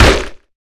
peachHit3.wav